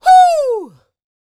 D-YELL 2102.wav